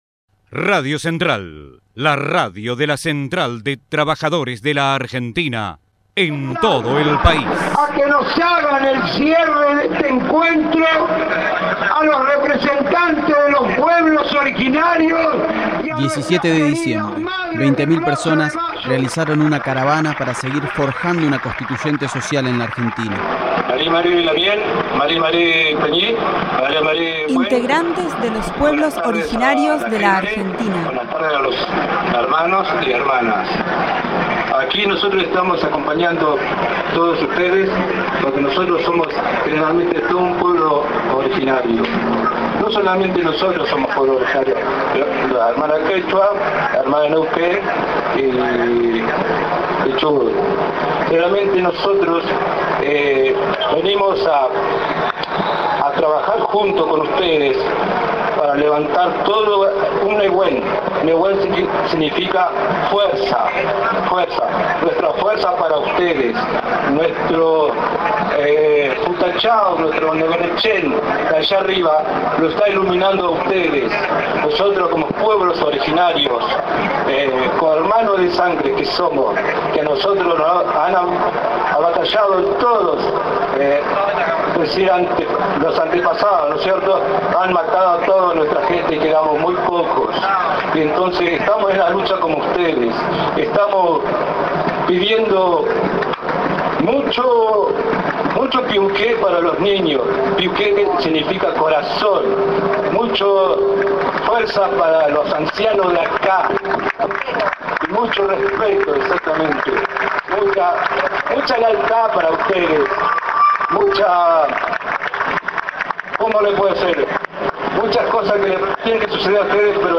Masivo acto frente al Congreso Nacional
17-12_PUEBLOS_ORIGINARIOS_MARCHA.mp3